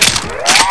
RiflePck.wav